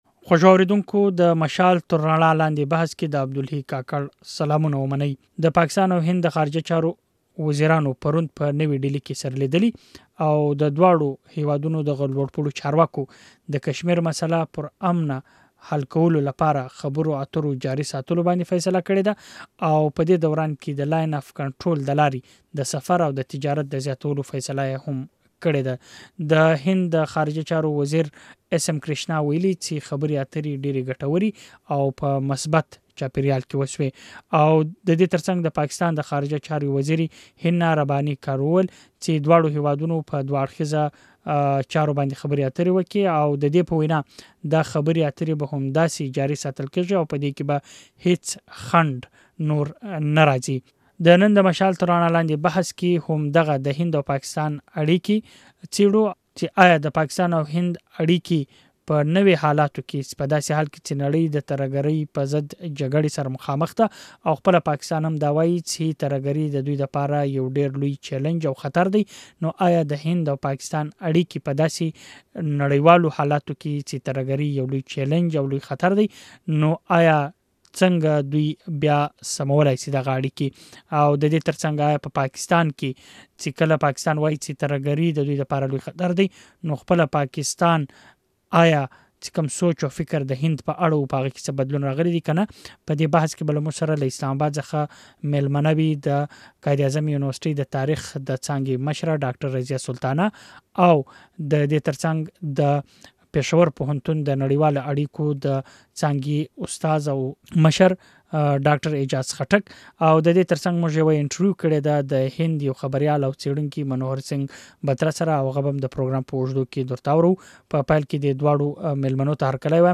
د مشال تر رڼا لاندې بحث همدې موضوع ته ځانګړی شوی چې د هند او پاکستان دایمي روغې پر وړاندې لوی خنډونه څه دي؟